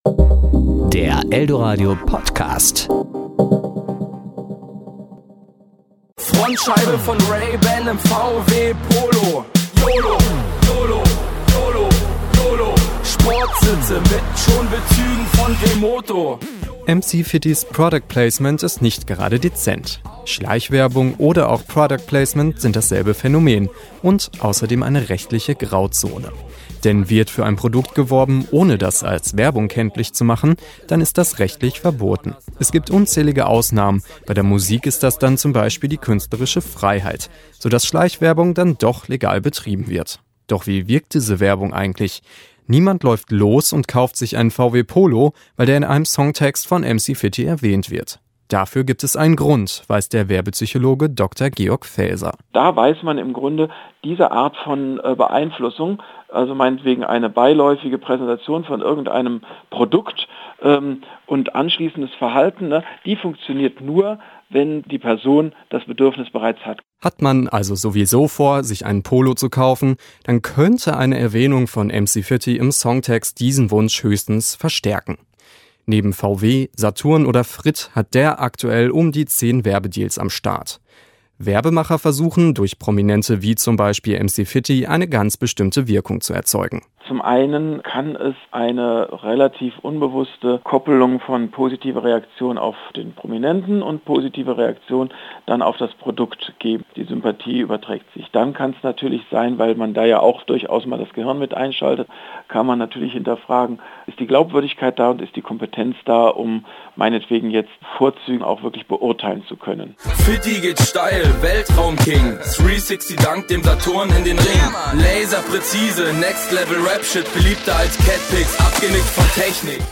Serie: Beiträge